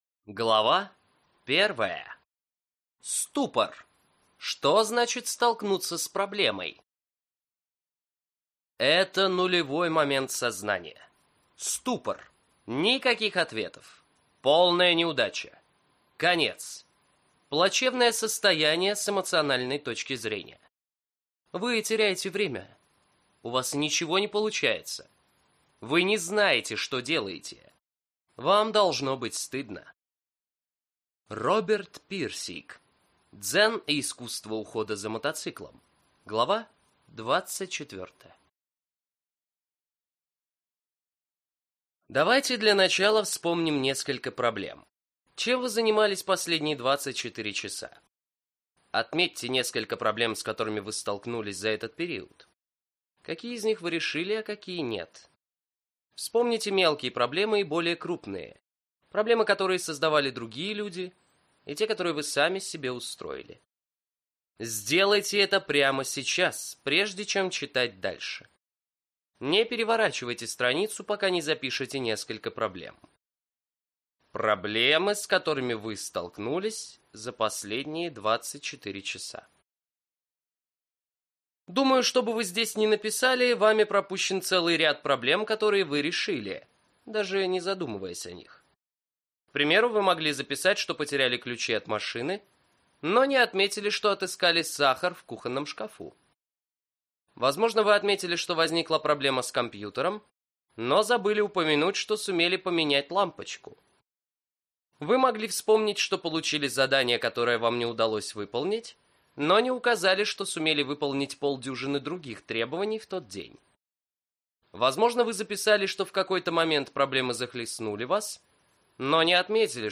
Аудиокнига Как решить любую проблему | Библиотека аудиокниг